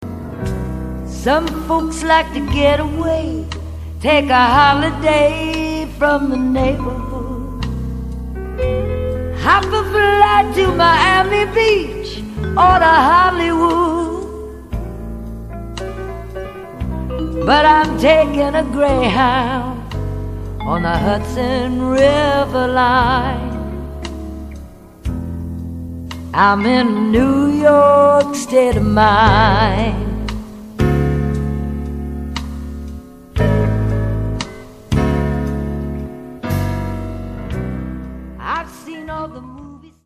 FREE SOUL/RARE GROOVE
より都会的なグルーヴとメロウなテイストを増したサード・アルバム